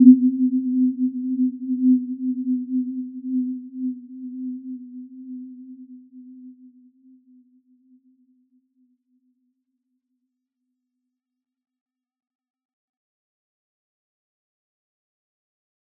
Warm-Bounce-C4-f.wav